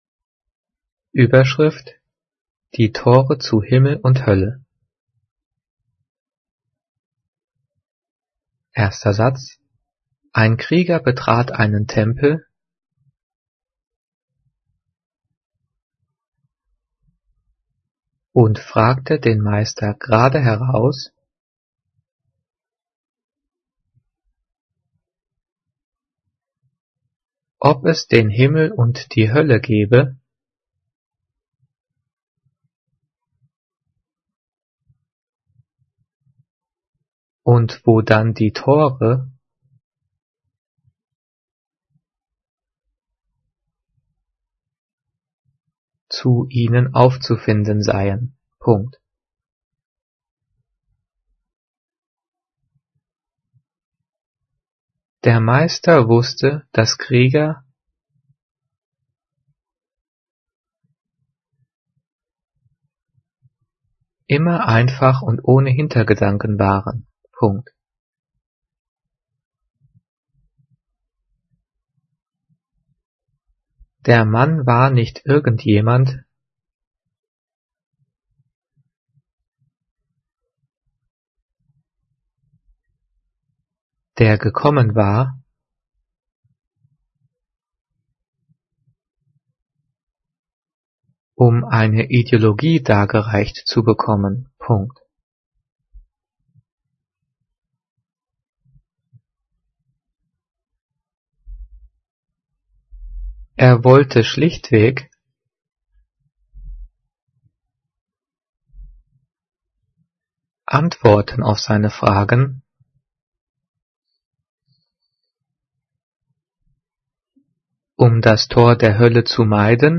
Die vielen Sprechpausen sind dafür da, dass du die Audio-Datei pausierst, um mitzukommen.
Diktiert: